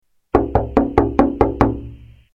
Window knock sound 2